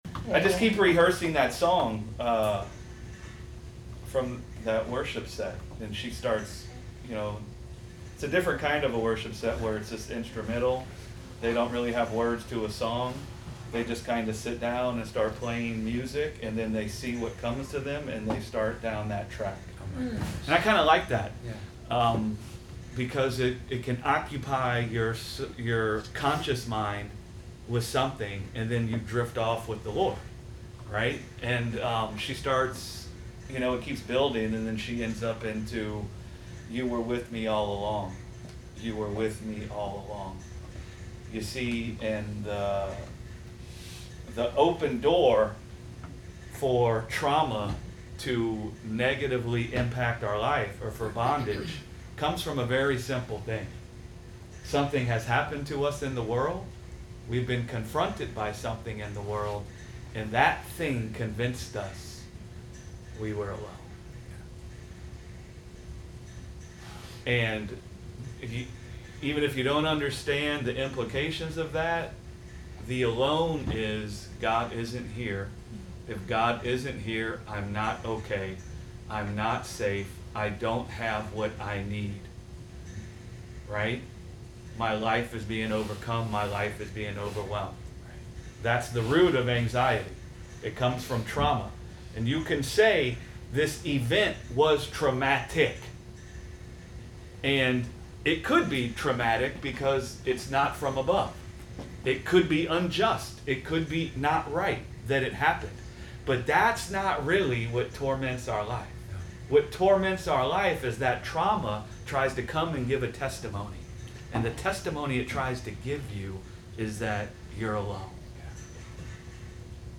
Sunday Bible Study: You Were With Me All Along - Gospel Revolution Church